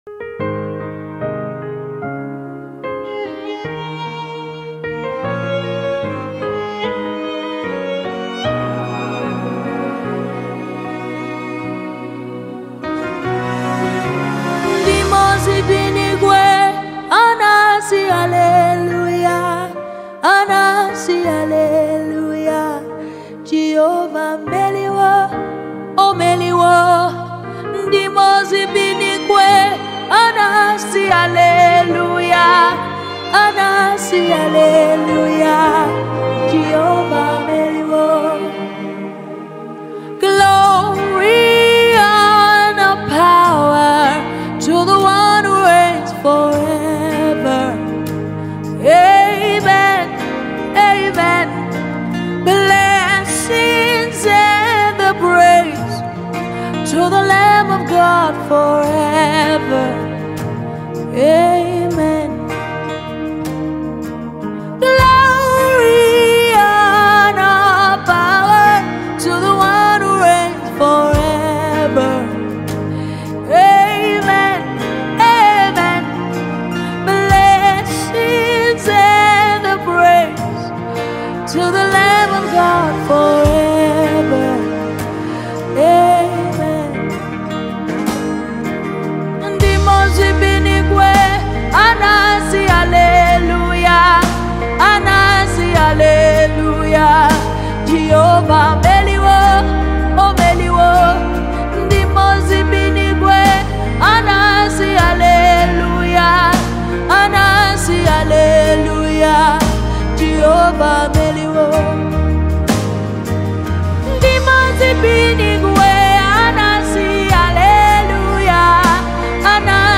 worship sounds